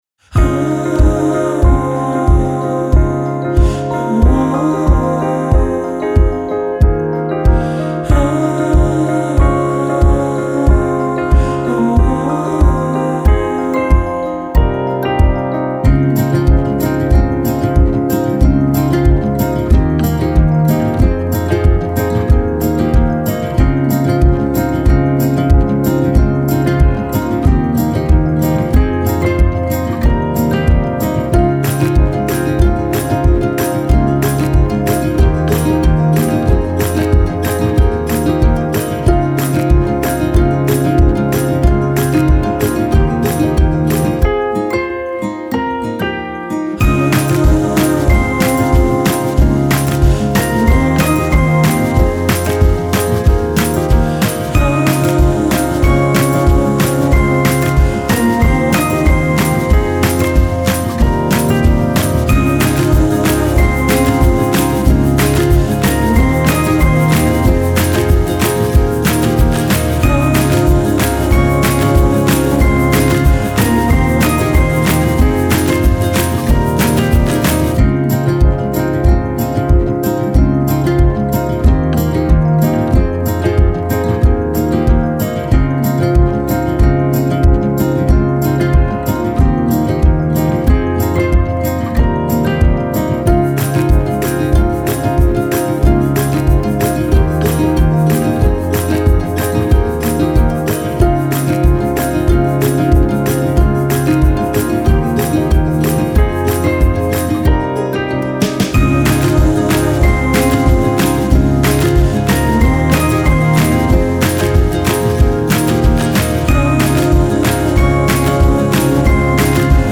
ACOUSTIC INDIE FOLK
Acoustic / Inspiring / Hopeful / Uplifting